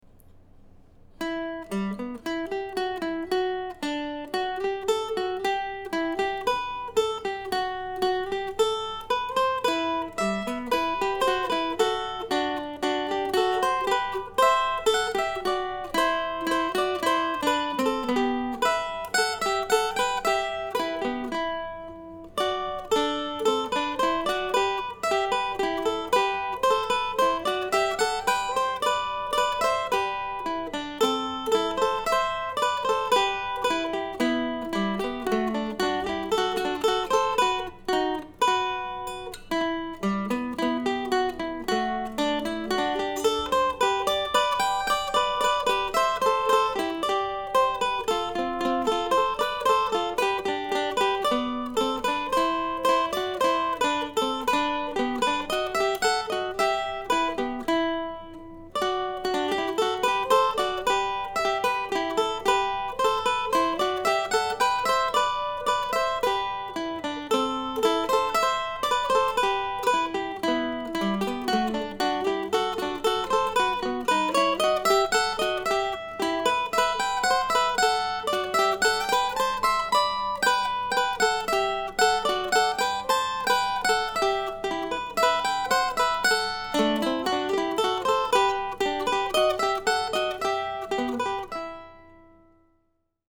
Ten Easy Duos - No. 3 ( mp3 ) ( pdf ) Another duo piece, fun to play. I was too warm, or lazy, to turn off the window fan while I recorded this one so you might hear some fan noise in the background. Also a neighbor decided to start mowing as I was trying to get a usable take and you might hear a little of that, especially near the end.